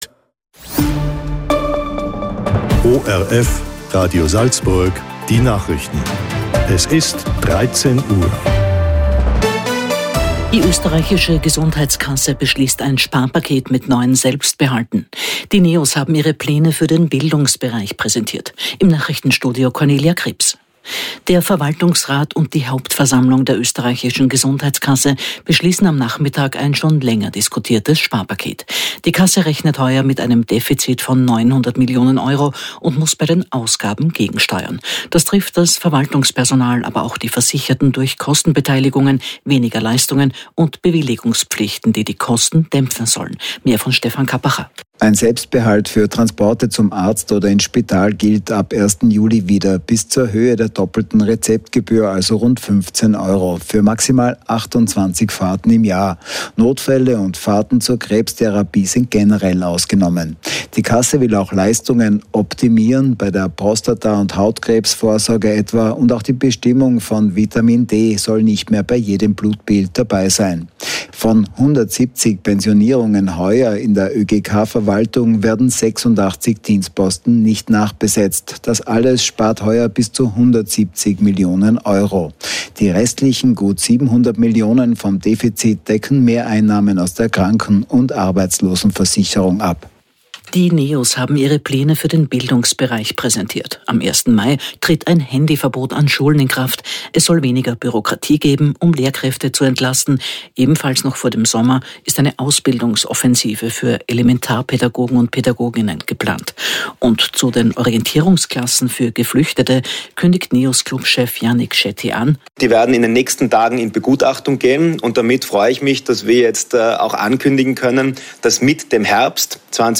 Am Dienstag, 29.04.2025 fand im Radio Salzburg eine Mittagszeit zum Thema "Nodendruck" statt.